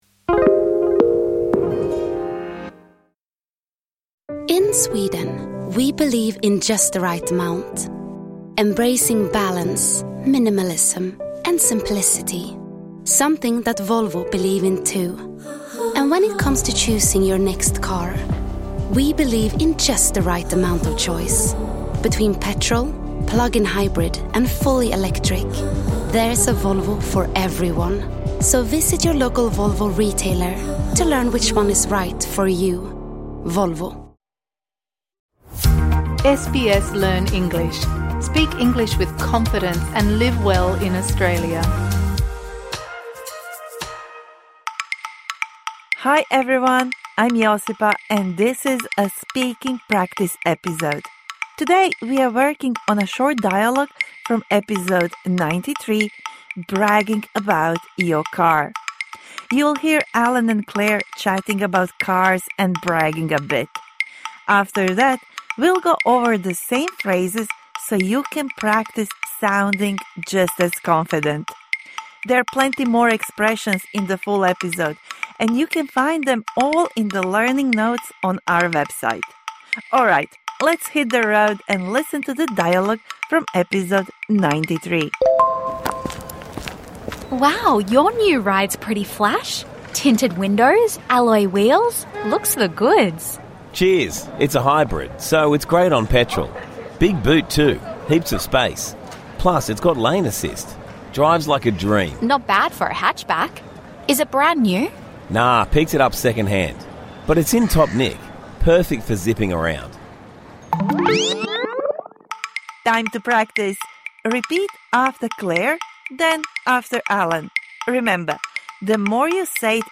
هذه الحلقة الإضافية تقدّم لك تدريبًا تفاعليًا على التحدّث باستخدام الكلمات والعبارات التي تعلّمتها في الحلقة 93: كيف تتحدث عن التباهي بسيارتك.